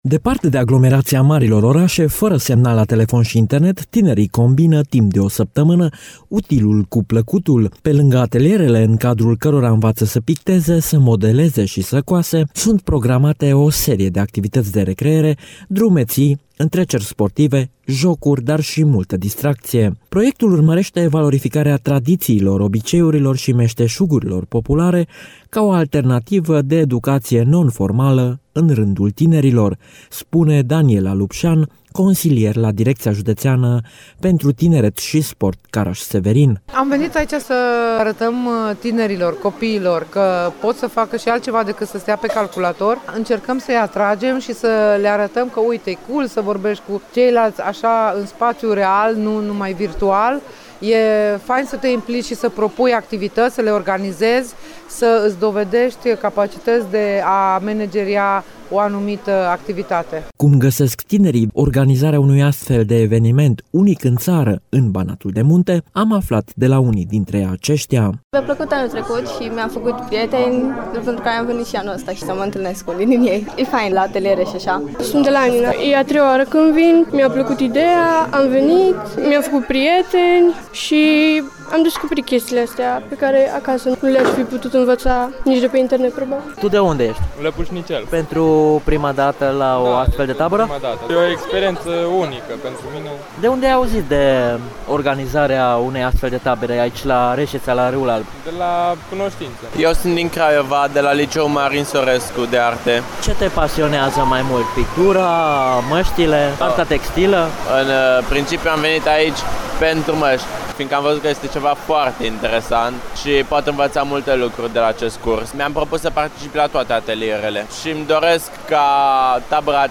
a fost în tabără şi consemnează: